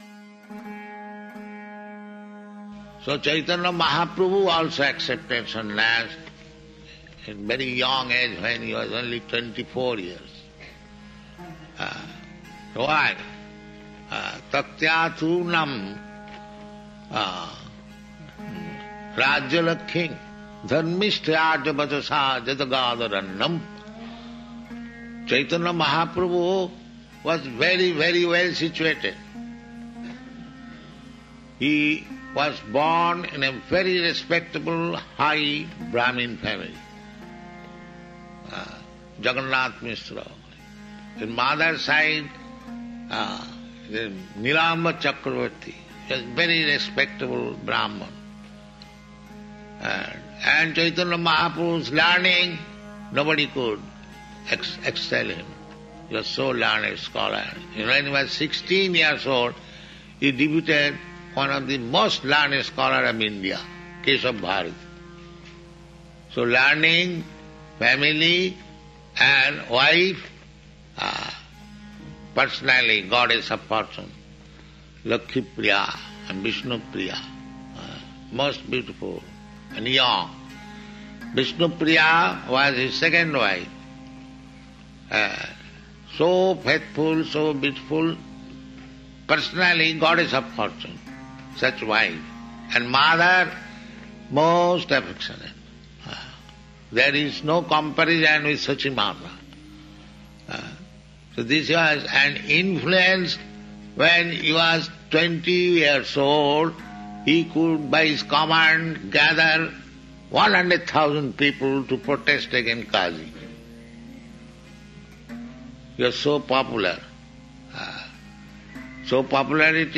(750721 - Lecture Initiation Sannyasa - San Francisco)